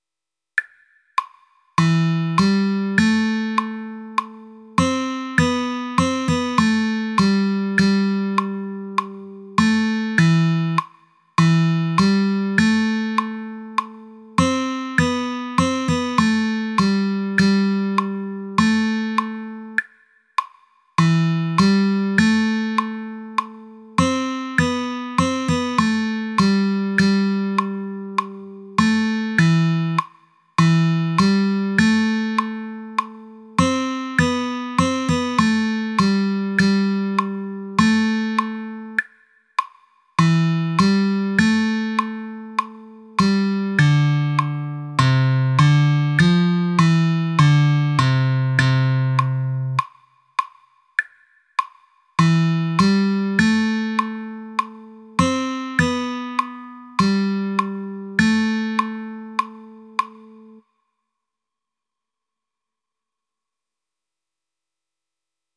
arreglo para flauta dulce